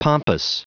Prononciation du mot pompous en anglais (fichier audio)
pompous.wav